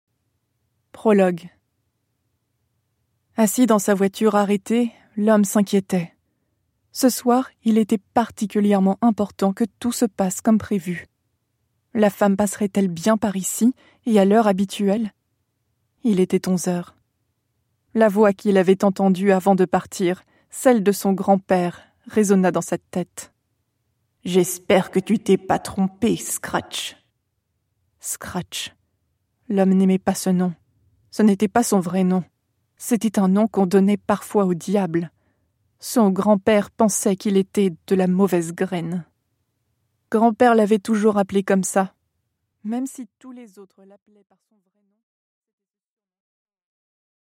Аудиокнига Les Pendules à l’heure | Библиотека аудиокниг